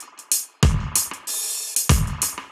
Index of /musicradar/dub-designer-samples/95bpm/Beats
DD_BeatA_95-01.wav